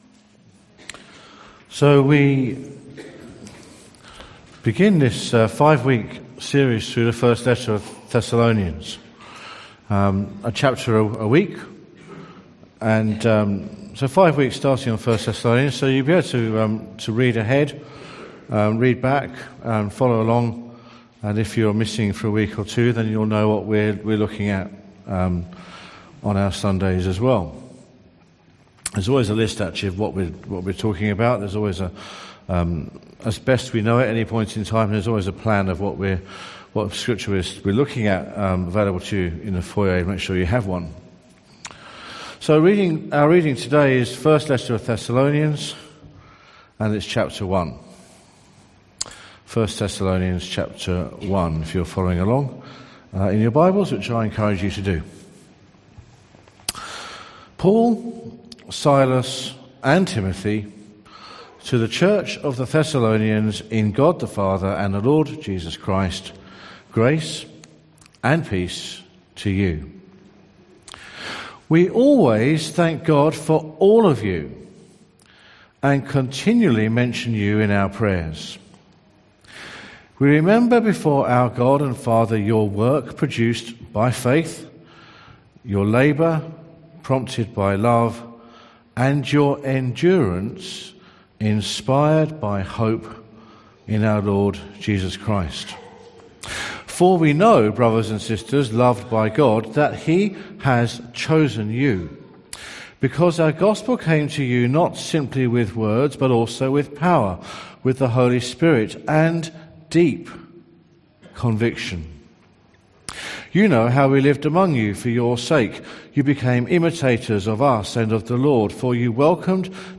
Bible reading and sermon from the 10AM meeting on 30/07/2017 at Newcastle Worship & Community Centre. The reading is taken from 1 Thessalonians 1:1-10.